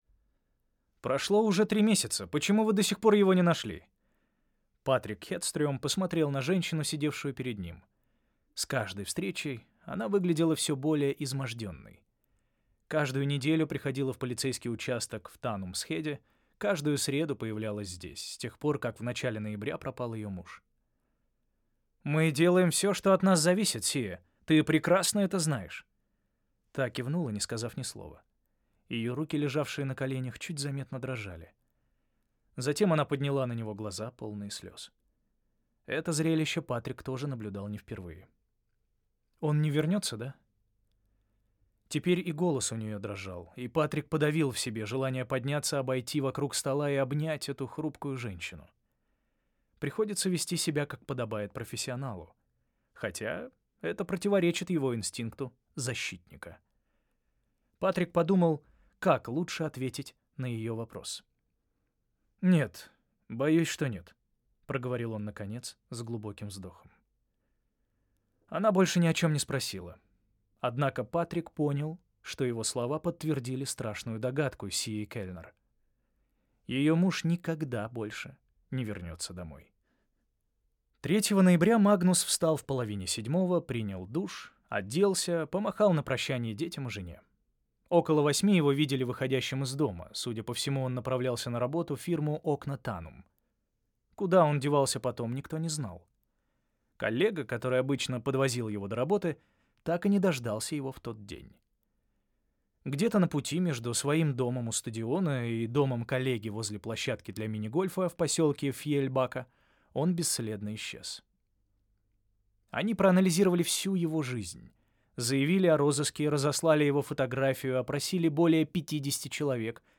Аудиокнига Письмо от русалки | Библиотека аудиокниг